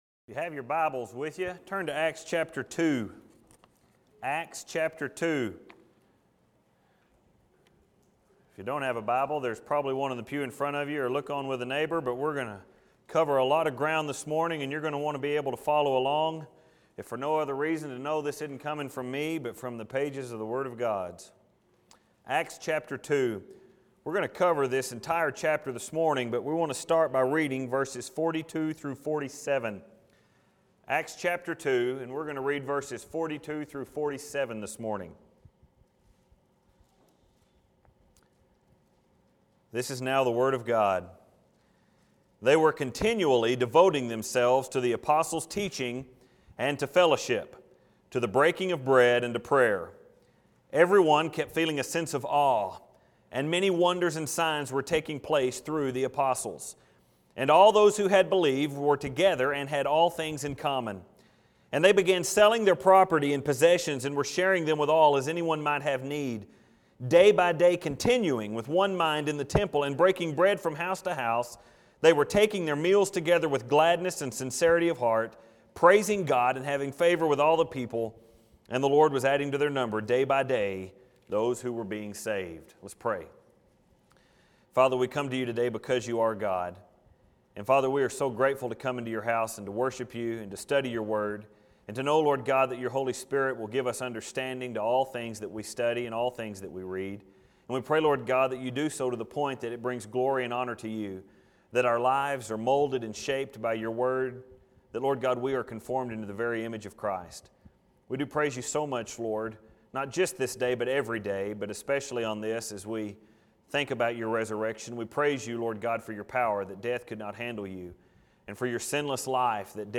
I am aware that if you read the title to the sermon this morning It was probably a little perplexing.